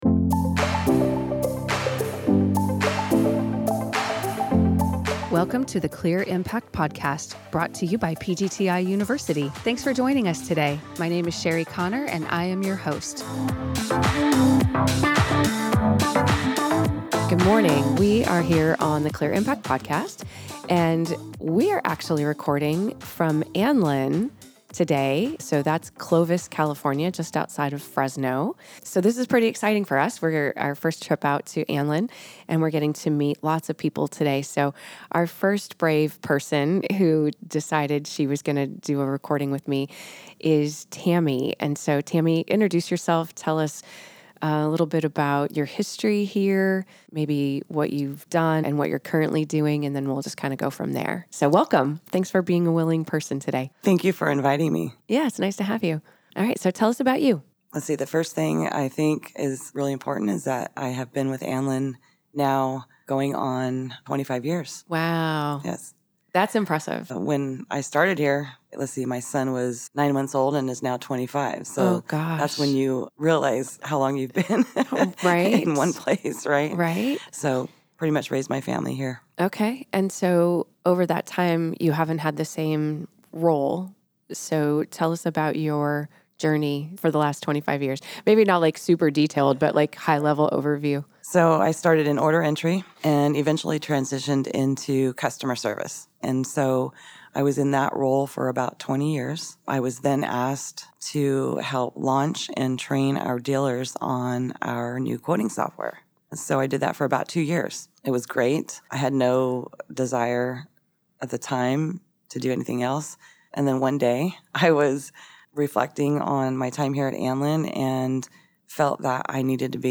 You'll enjoy this conversation.